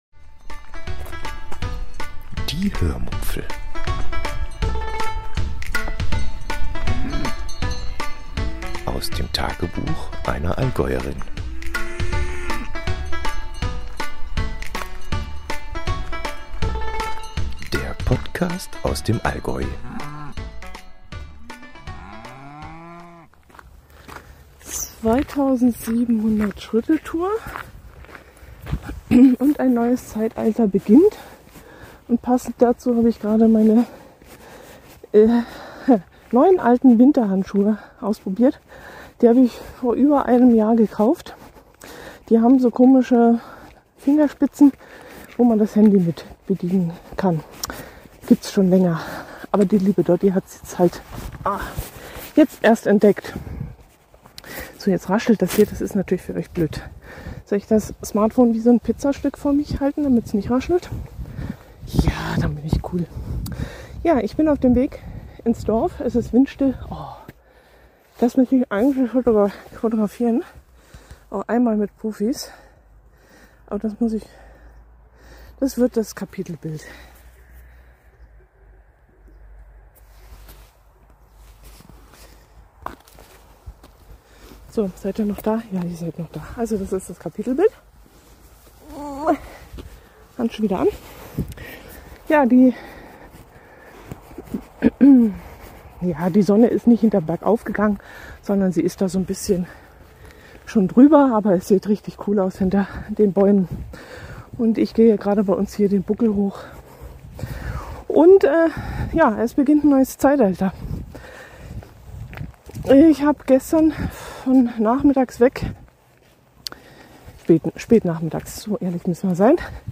In dieser Folge erzähle ich euch, warum ich mich nach dem Hack meiner Seite entschieden habe, neu anzufangen. Ab jetzt wird der Podcast allerdings freier und spontaner – ich werde viele Folgen künftig unterwegs aufnehmen, z.B. wie heute auf meiner 2700-Schritte-Tour ins Dorf. Das bringt viel Atmo mit sich – da müsst ihr zukünftig durch. Außerdem geht es dieses Mal um einen Instagramer, der sich einen Buzzcut schneiden und grün färben ließ.